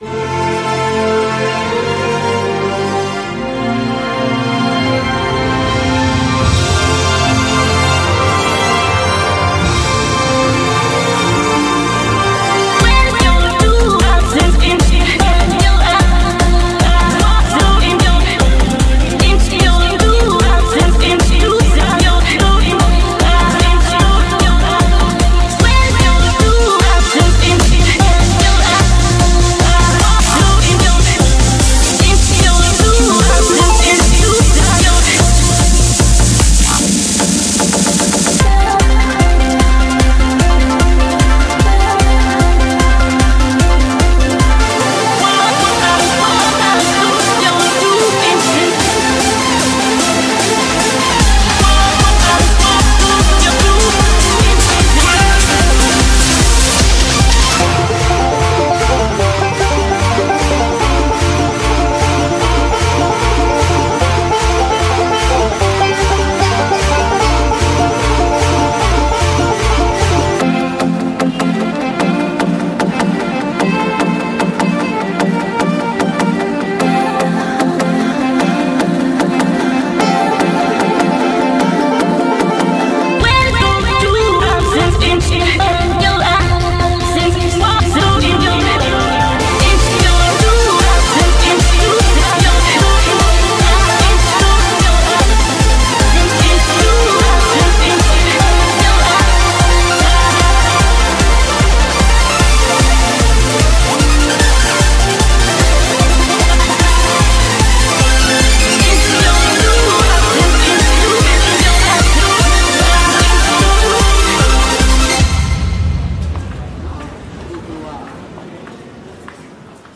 BPM75-150
Audio QualityLine Out